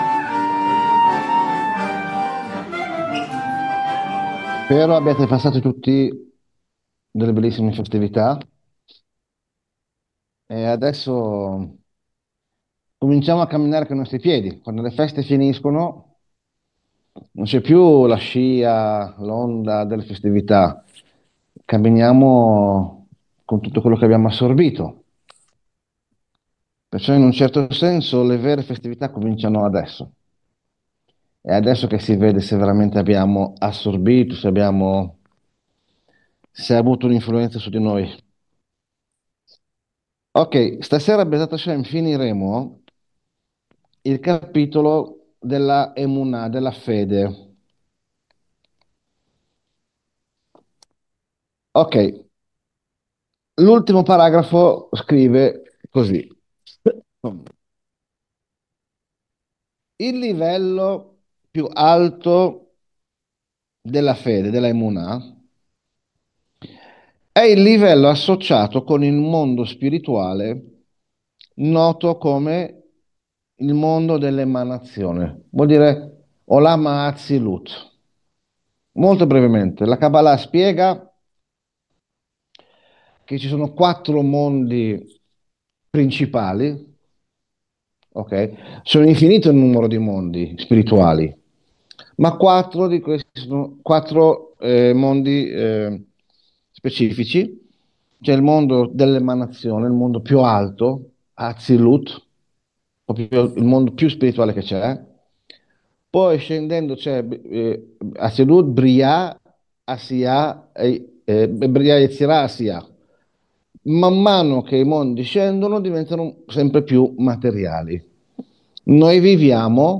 Lezione del 20 ottobre 2025